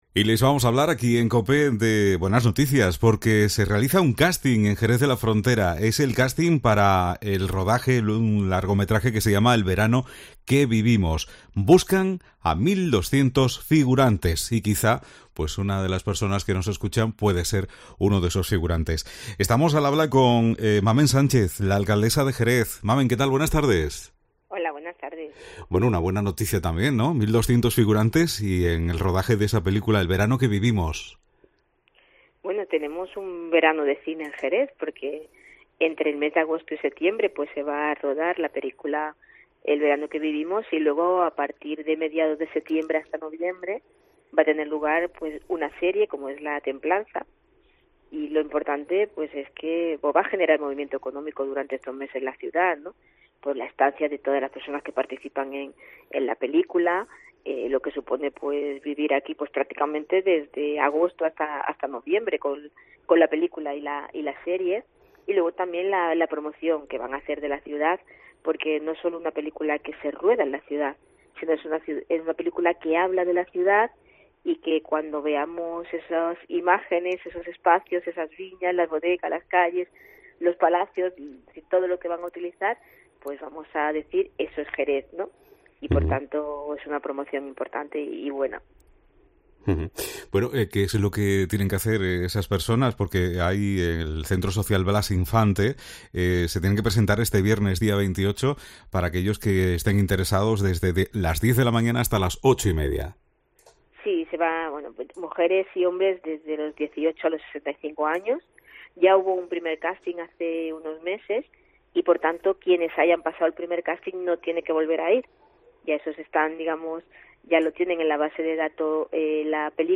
Mamen Sánchez, alcaldesa de Jerez, en COPE